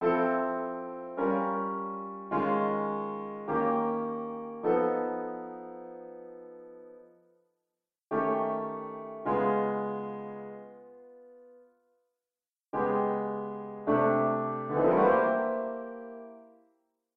Key written in: C Major
How many parts: 4
Type: Other male
All Parts mix: